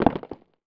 CONSTRUCTION_Rocks_01_mono.wav